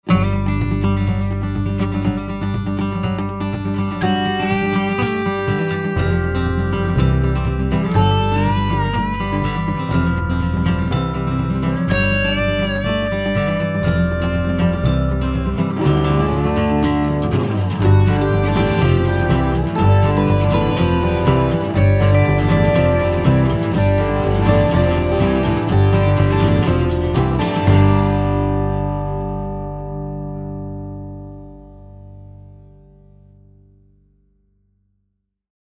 showcasing me on guitar, lapsteel, & bass